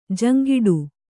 ♪ jaŋgiḍu